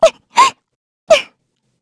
Xerah-Vox_Sad_kr.wav